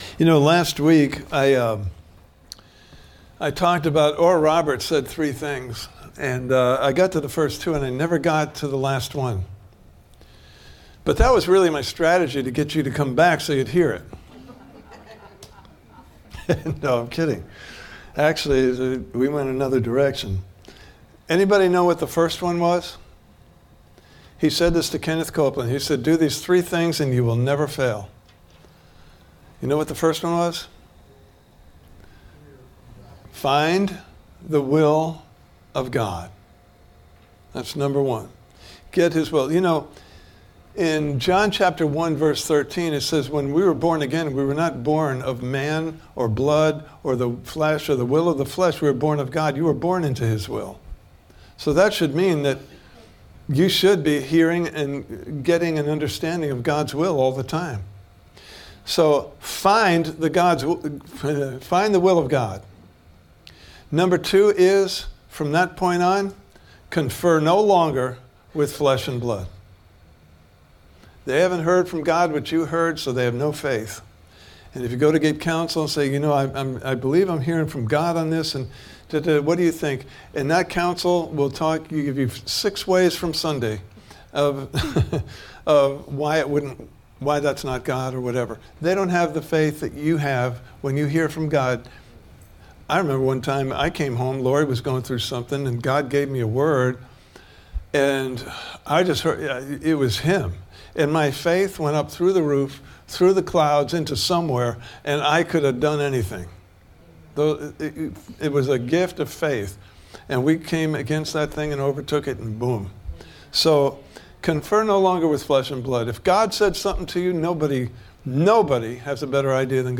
Service Type: Sunday Morning Service « Part 1: Hearing vs. Listening (Video) Part 2: Listening with Your Heart?